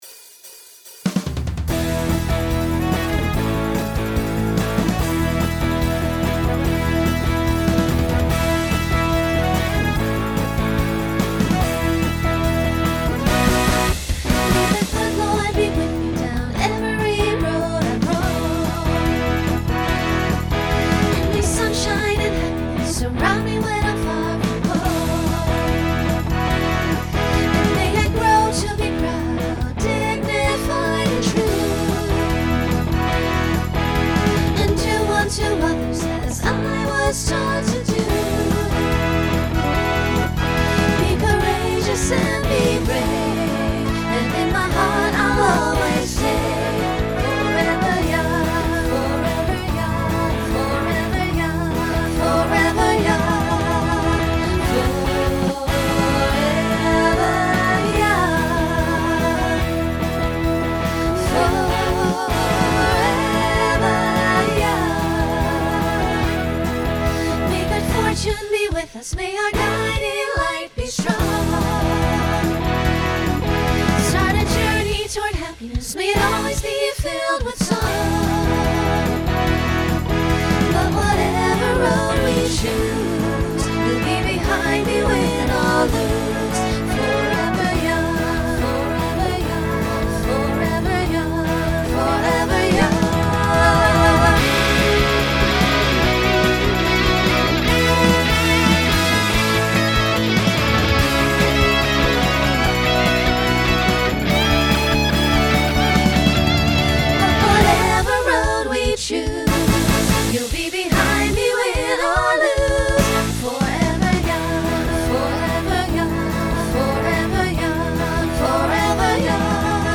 New SATB voicing for 2026.
Genre Rock Instrumental combo